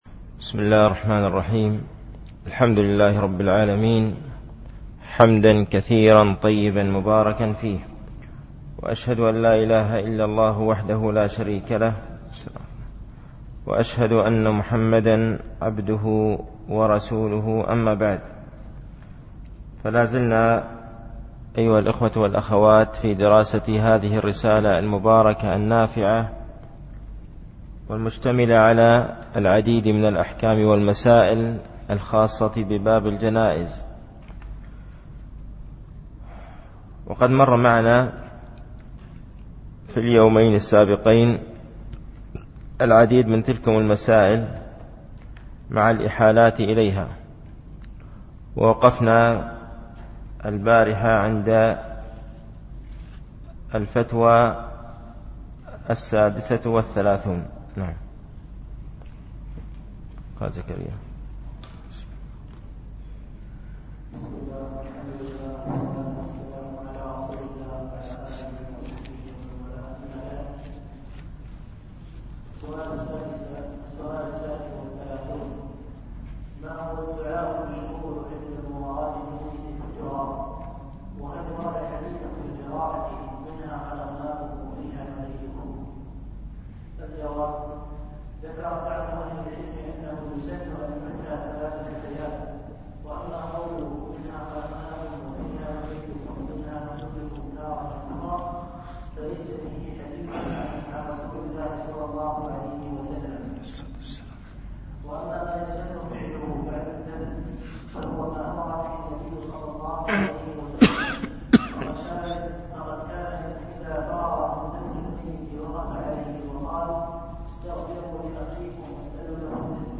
التعليق على رسالة 70 سؤالا في أحكام الجنائز ـ الدرس السادس